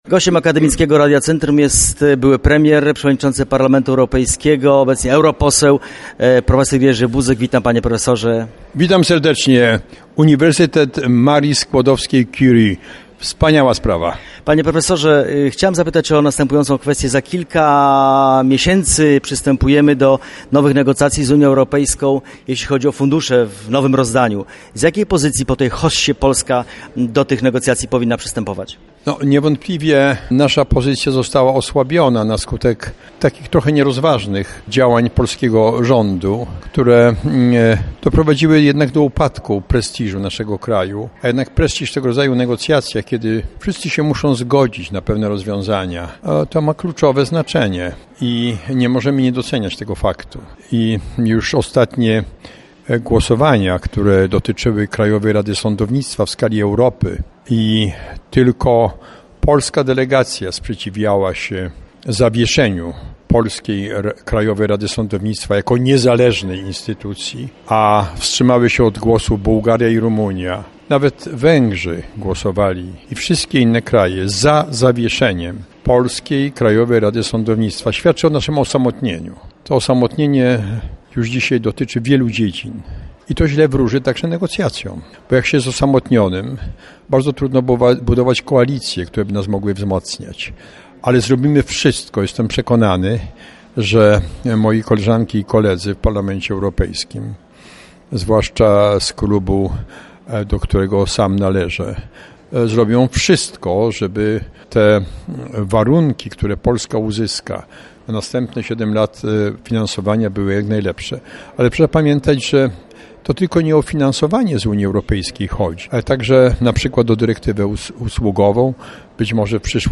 Gościem specjalnym pierwszego dnia Kongresu Kultury Województwa Lubelskiego, był dzisiaj (19.09) profesor Jerzy Buzek. Akademickiemu Radiu Centrum jako jedynej stacji były premier i były przewodniczący Parlamentu Europejskiego udzielił krótkiego wywiadu.